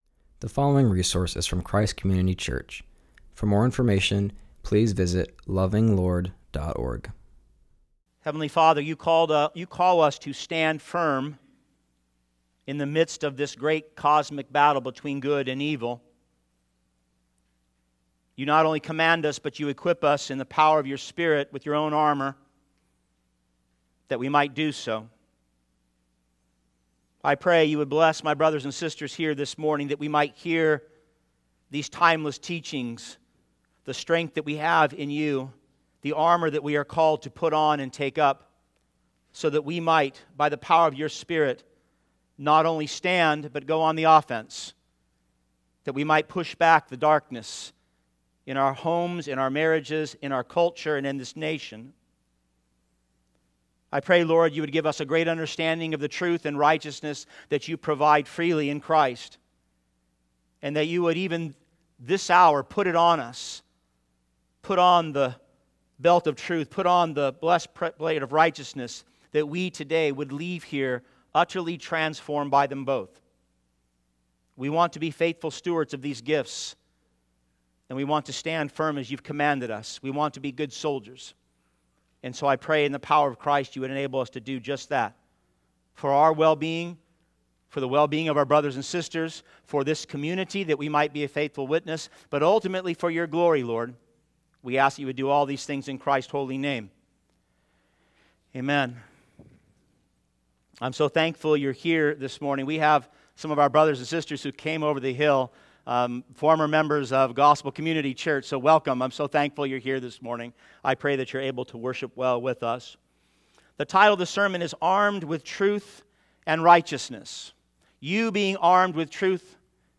continues our series and preaches from Ephesians 6:14.